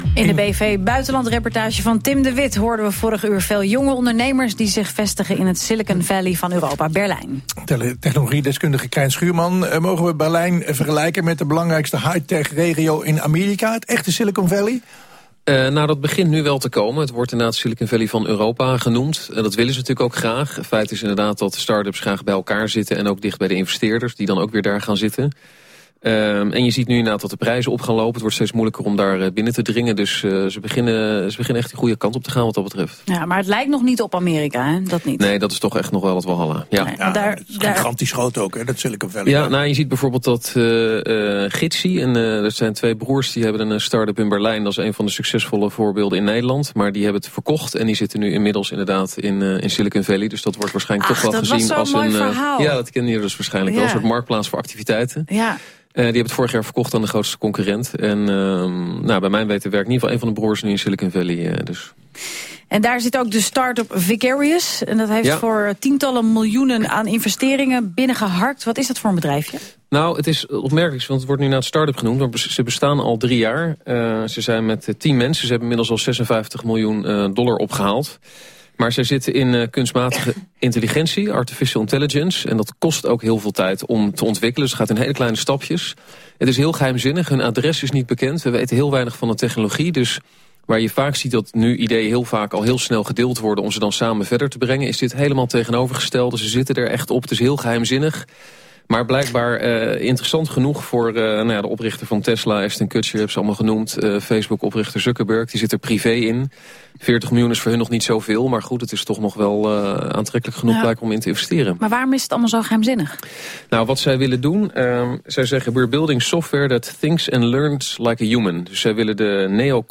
Ik praatte erover op radio 1: